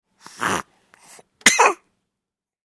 Звуки детского храпа
Звуки малыша во сне с храпом и кашлем